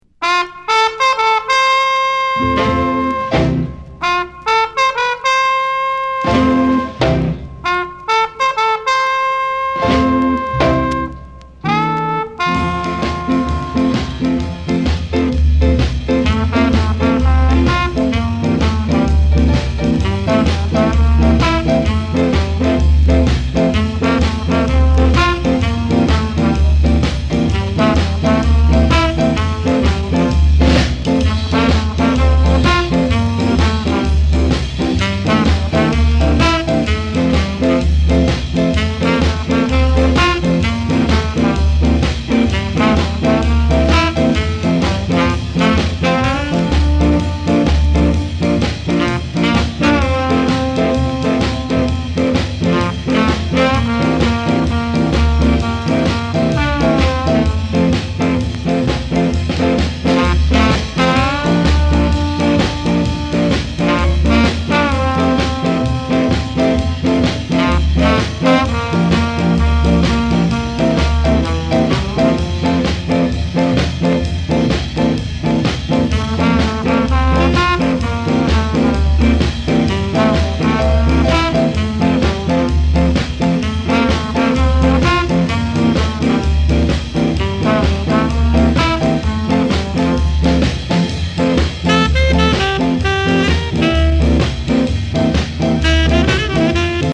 スカ・レゲエ
60年代中期のヴィンテージ感がたまらんです。